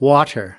Amerikan Aksanı: Vatır
US /ˈwɑː.t̬ɚ/
Amerikan Aksanı: